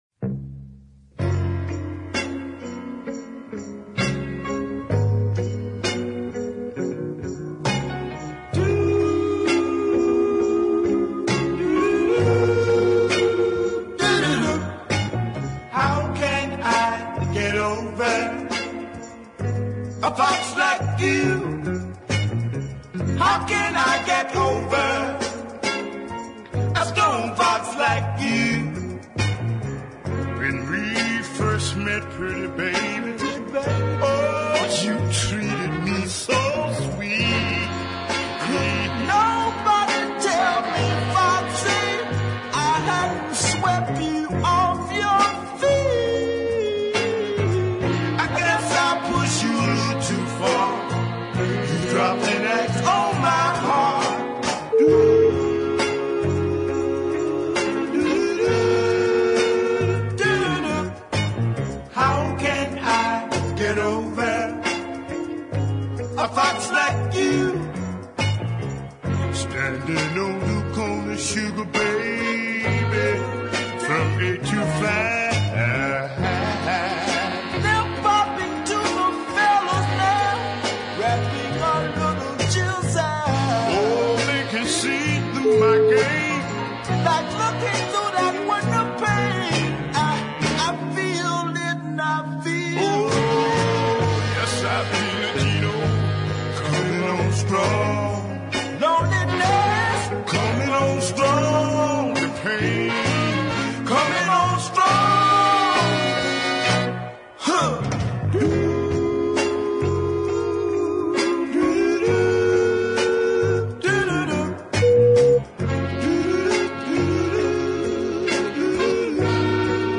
is a plodding deep ballad of great power